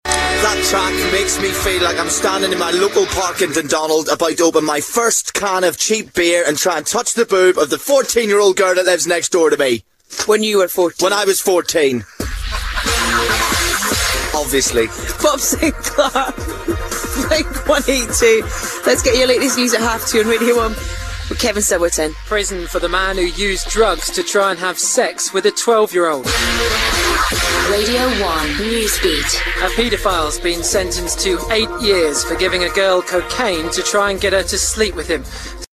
Always a good idea to check the main headline before putting the mic up before a news bulletin...